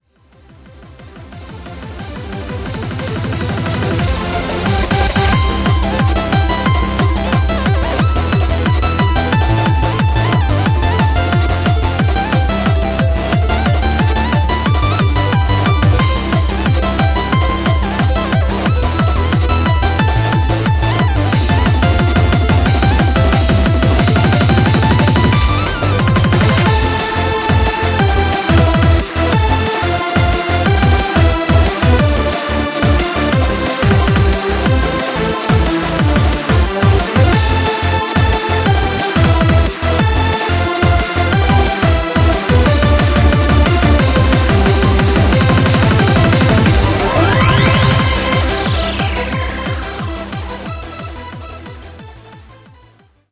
02 HAPPY HARDCORE